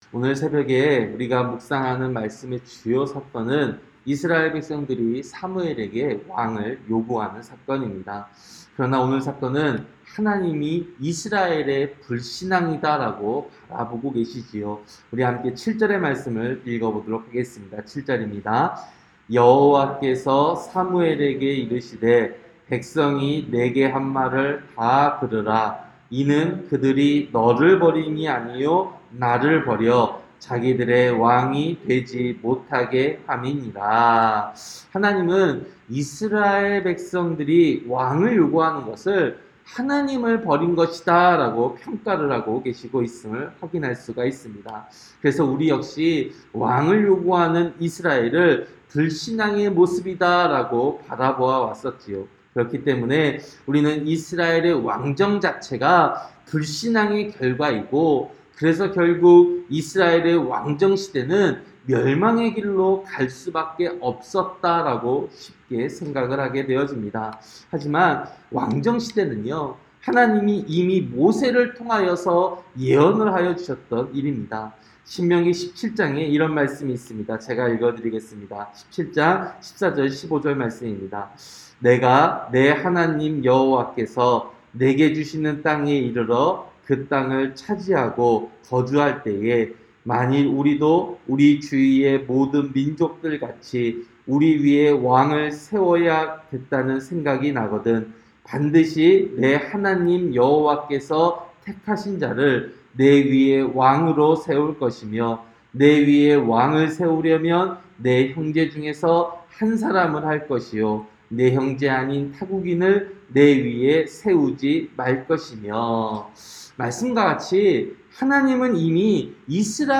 새벽설교-사무엘상 8장